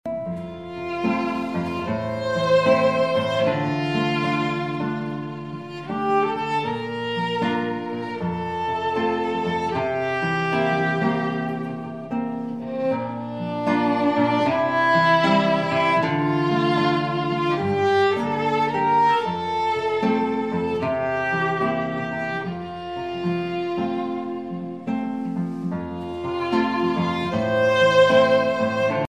красивые
спокойные
без слов
скрипка
Violin
Инструментальная версия со скрипкой вместо вокала